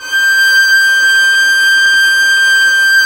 Index of /90_sSampleCDs/Roland L-CD702/VOL-1/STR_Violin 4 nv/STR_Vln4 no vib